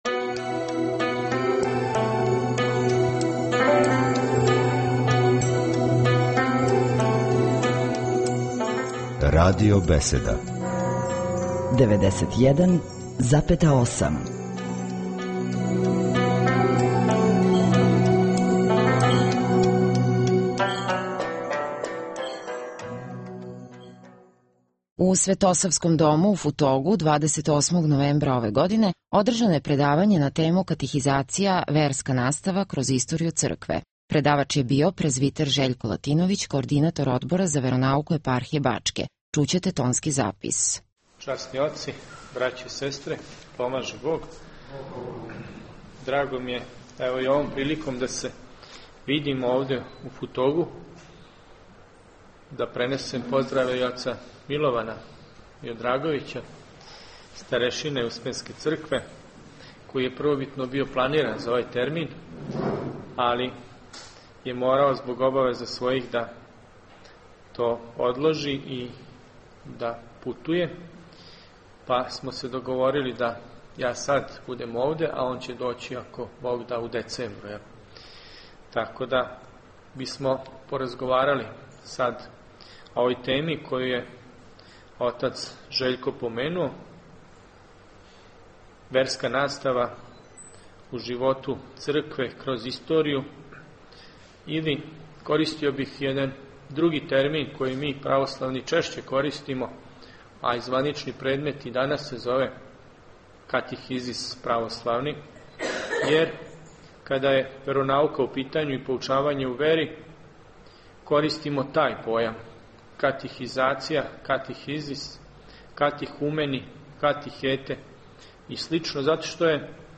У Светосавском дому у Футогу, 28. новембра 2010. године, одржано је предавање на тему Катихизација (Верска настава) кроз историју Цркве.